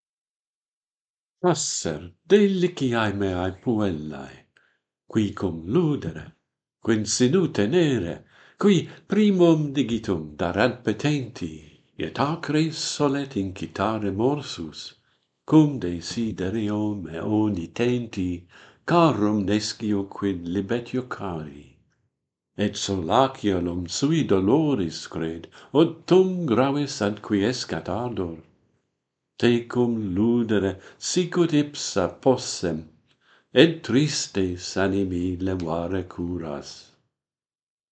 Passer, deliciae meae puellae - Pantheon Poets | Latin Poetry Recited and Translated